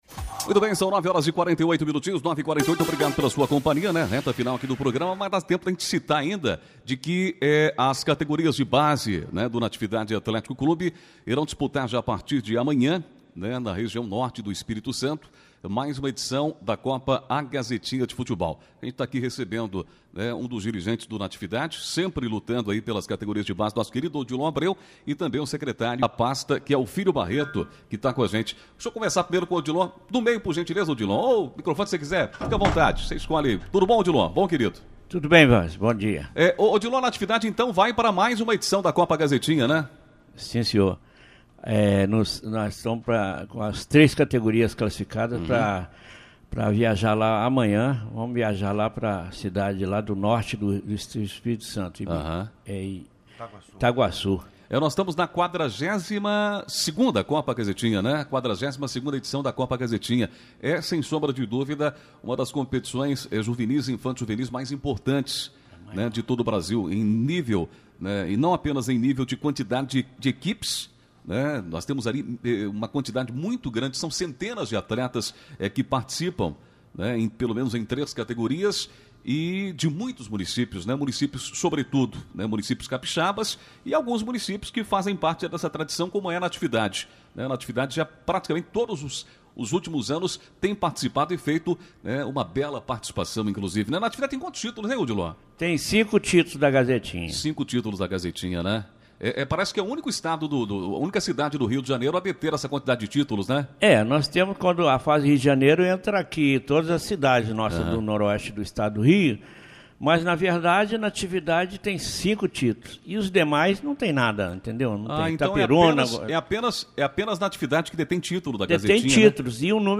4 janeiro, 2018 ENTREVISTAS, NATIVIDADE AGORA
ENTREVISTA-GAZETINHA.mp3